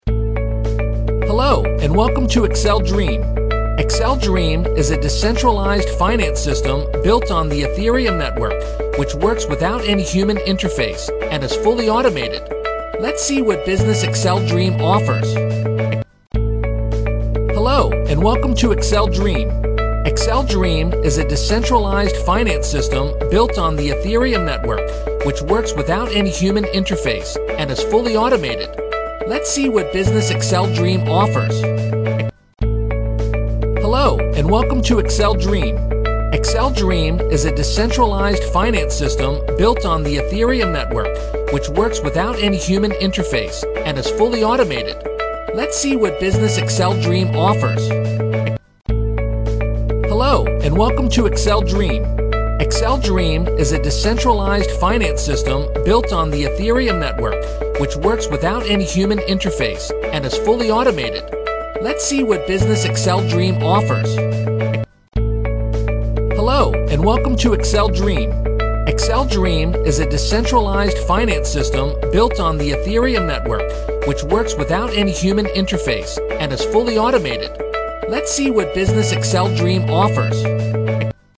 英语配音美式英语配音
• 男英5 美式英语 科技感 产品介绍 年轻清脆 激情激昂|大气浑厚磁性|科技感|积极向上|时尚活力|素人|科技感